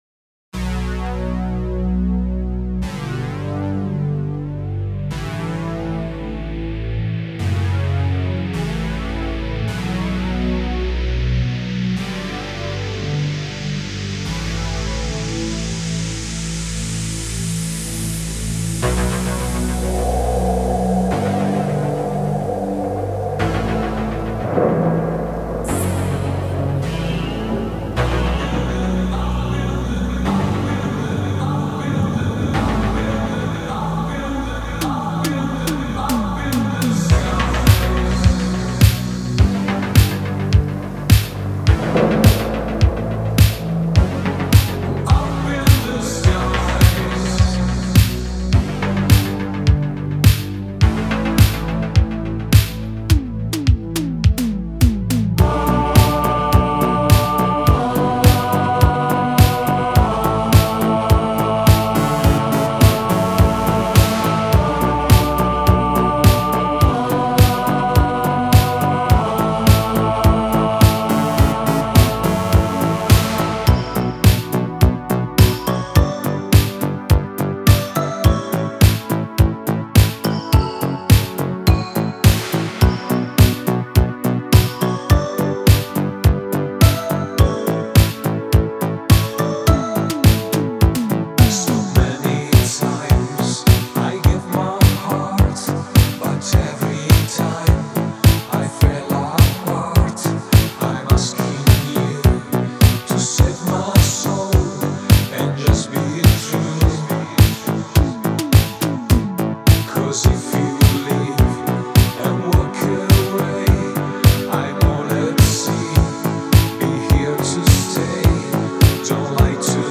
Disco Italo Disco